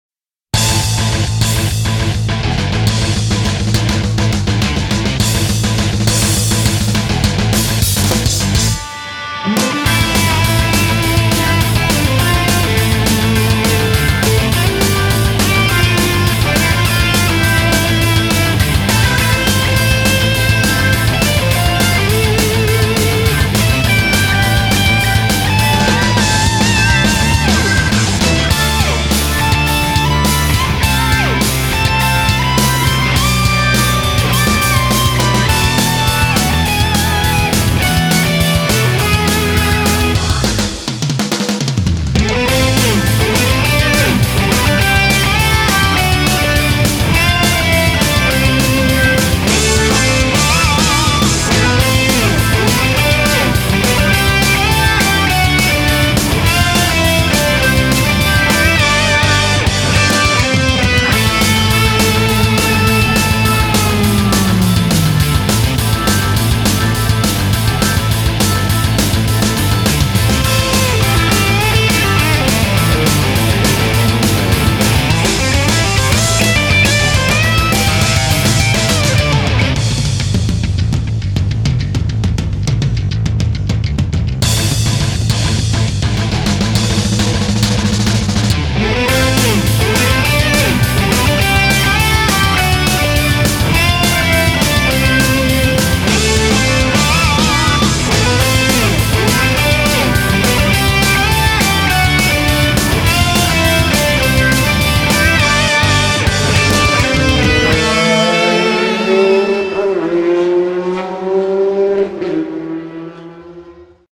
BPM103-206
Audio QualityPerfect (High Quality)